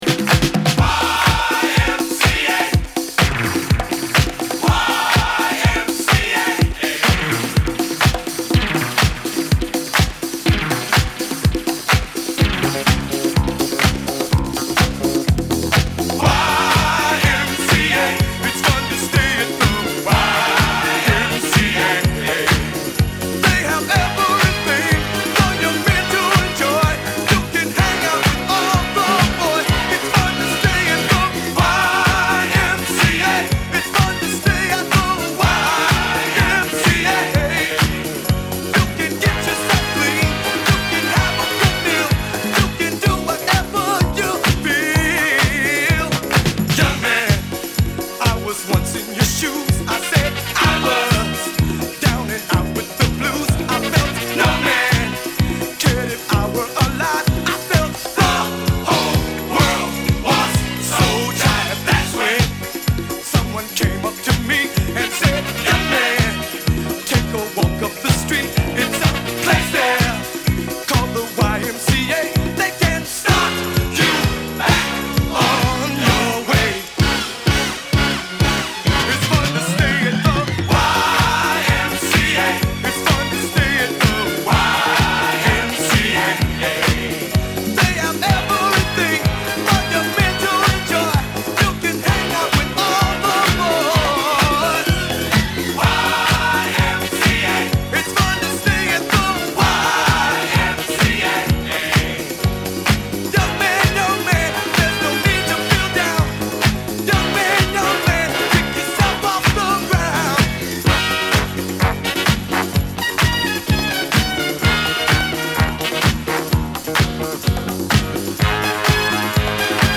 mixed as a continuous groove